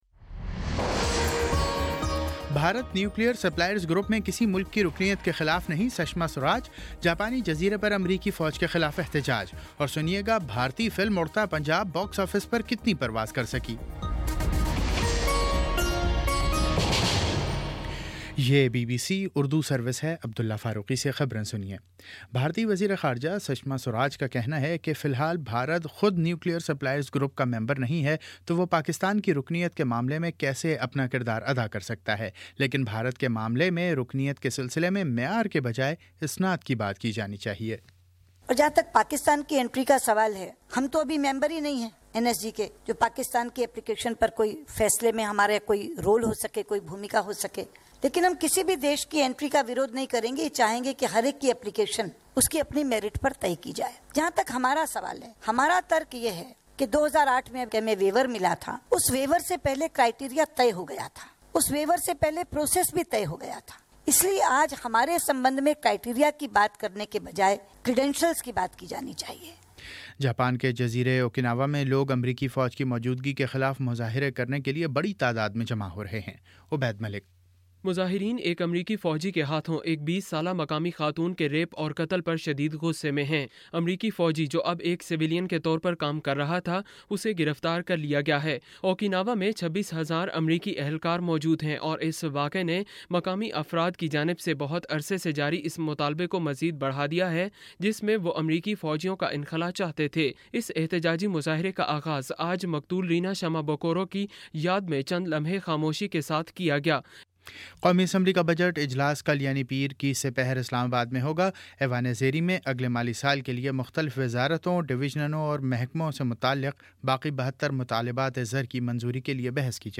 جون 19 : شام پانچ بجے کا نیوز بُلیٹن